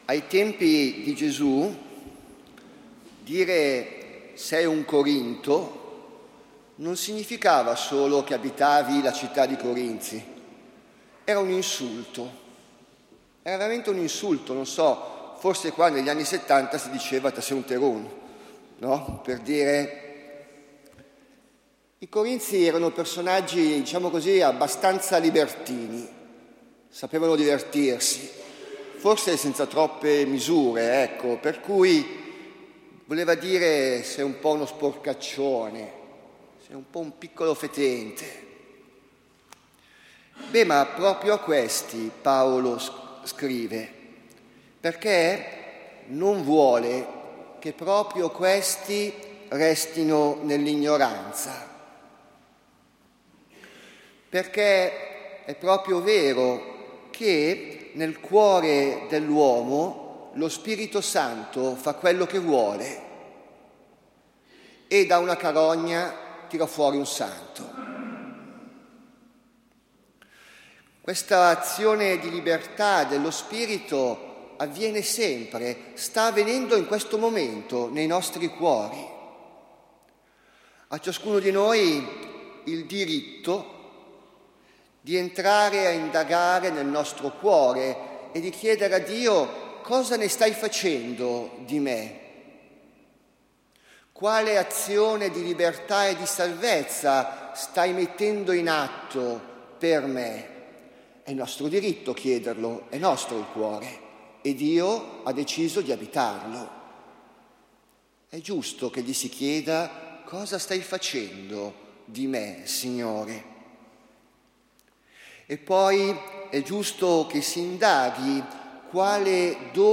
Categoria: Omelie